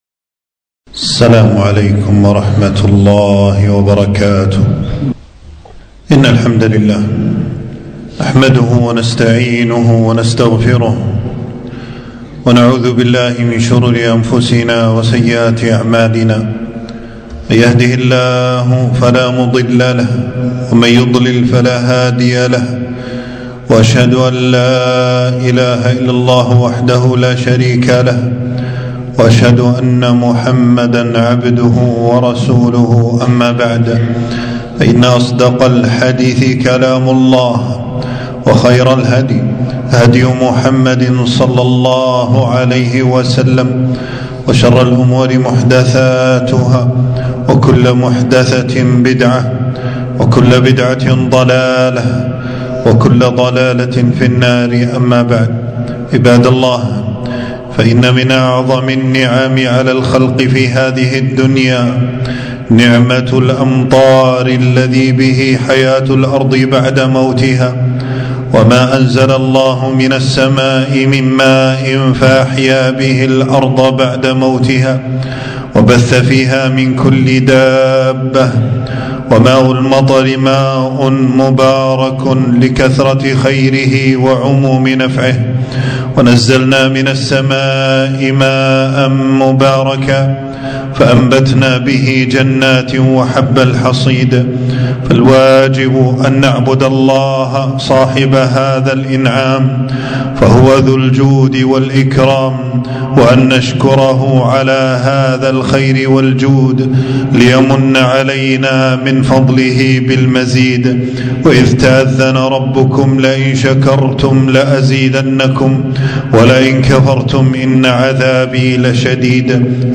خطبة - مطرنا بفضل الله ورحمته